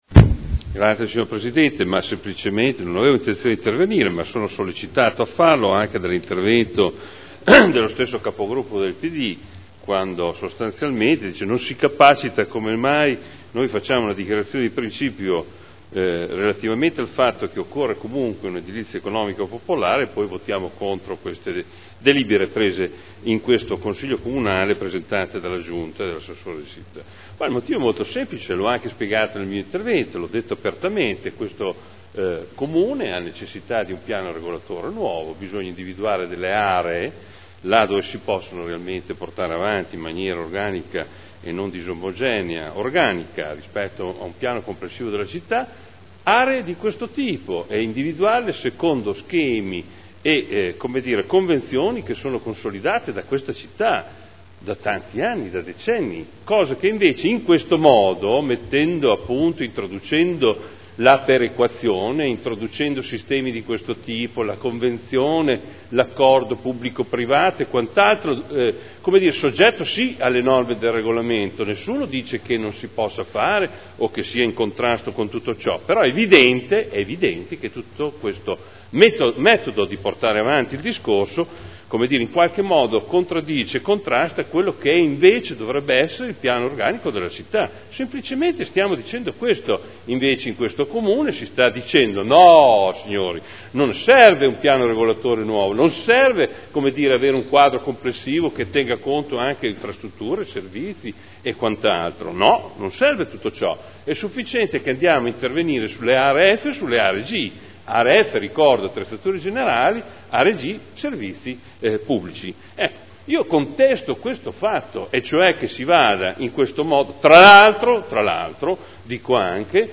Seduta del 14 novembre Zona elementare n. 50 Area 13 di proprietà comunale - Parere favorevole all'attuazione delle previsioni urbanistiche dell'area con permesso di costruire convenzionato in deroga al Piano particolareggiato (Art. 31.23 RUE) Dichiarazioni di voto